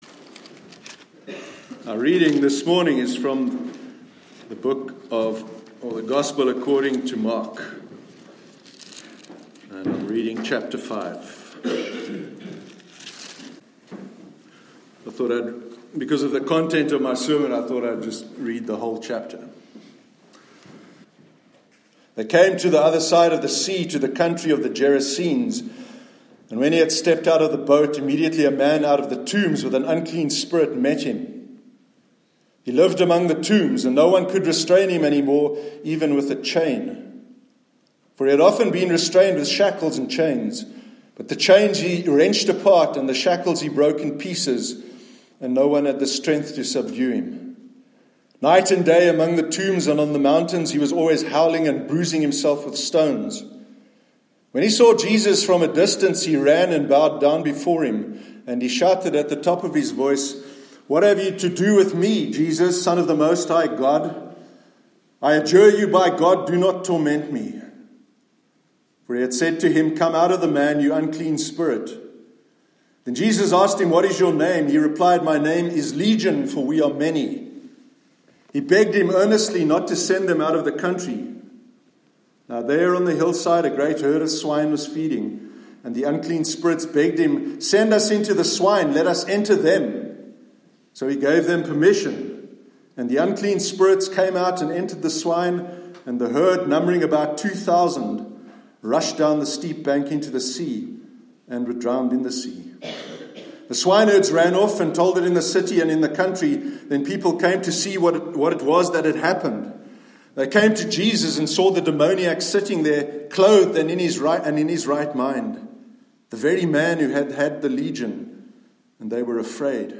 Purity and Impurity- Sermon 10th March 2019